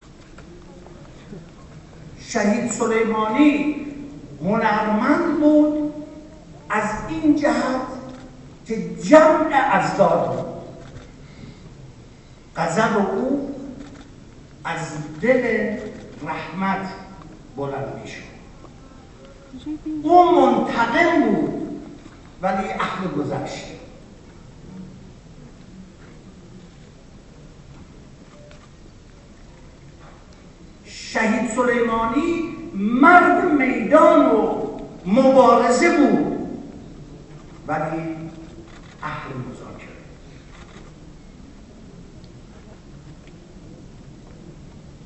همایش موسیقایی «مقام حماسه» اجرای ارکستری موسیقی به یاد شهید سلیمانی با حضور رئیس مجلس و وزیر ارشاد در تالار وحدت برگزار شد.